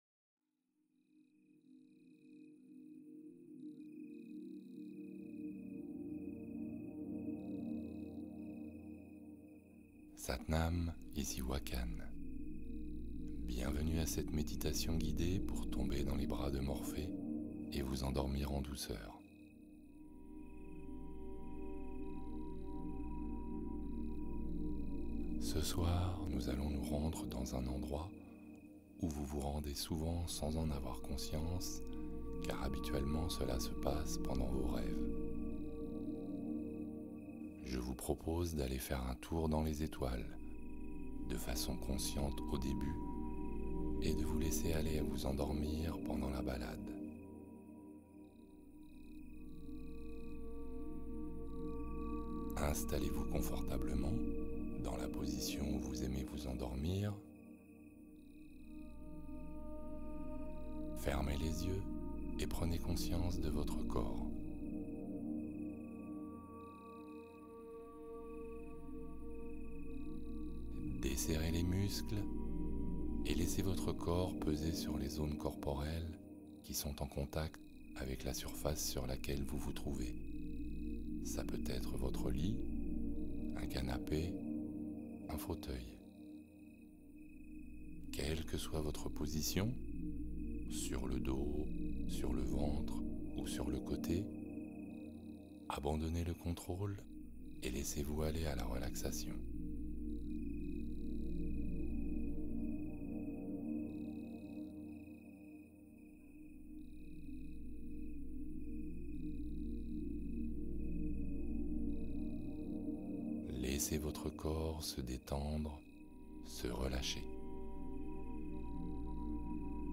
Sommeil profond : hypnose pour glisser dans un repos naturel